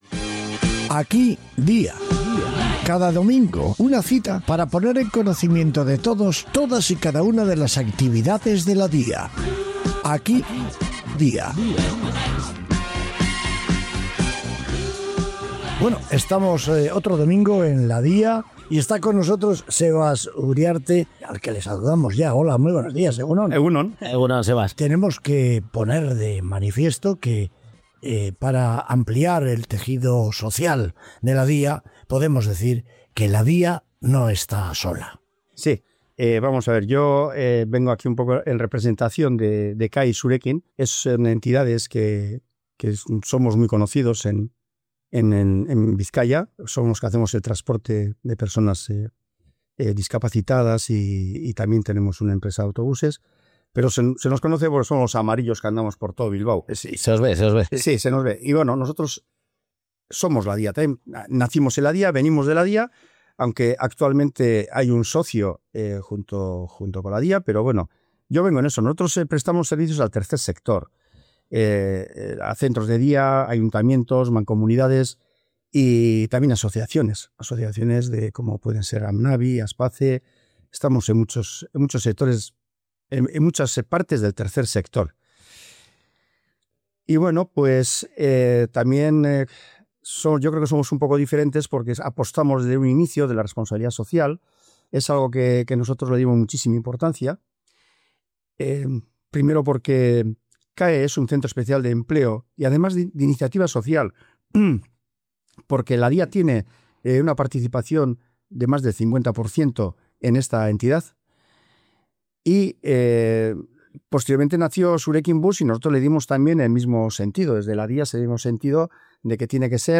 Una charla que pone el foco en el tercer sector, la responsabilidad social real y el valor de las alianzas entre entidades.